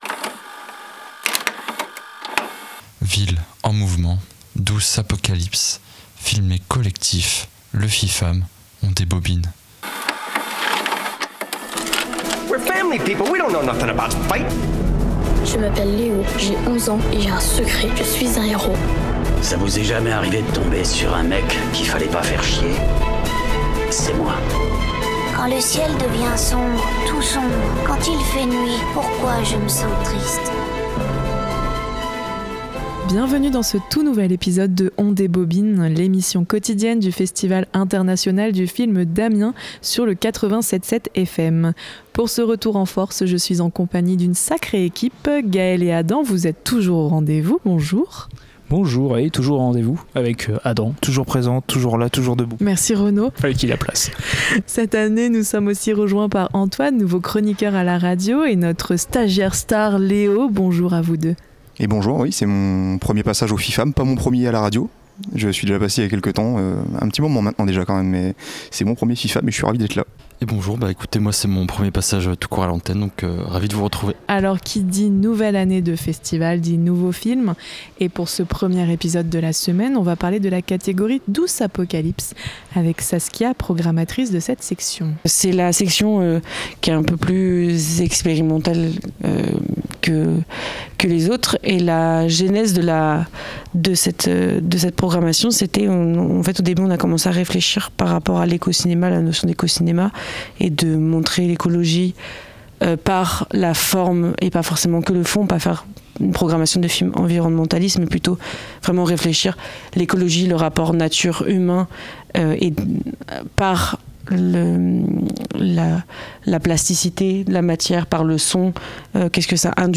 nous allons aborder les différents thèmes du festival, avec des interviews, des debriefs et d’autres petites surprises.